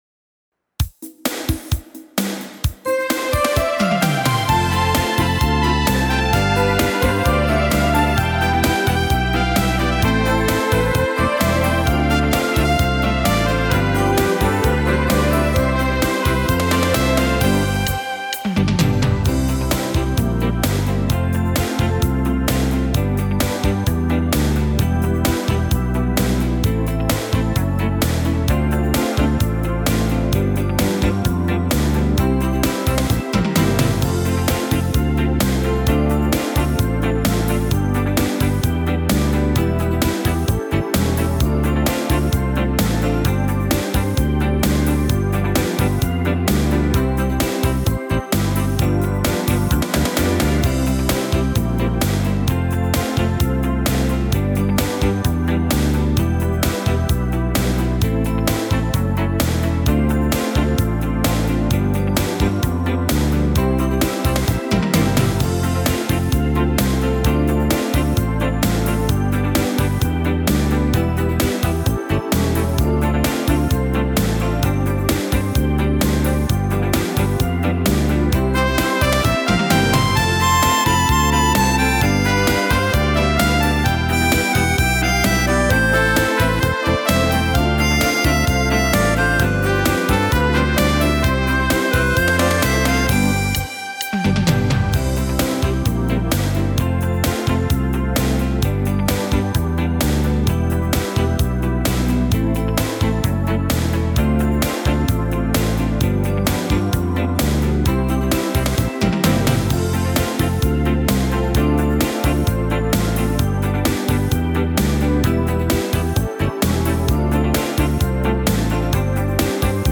Tone Nữ (C)
KARAOKE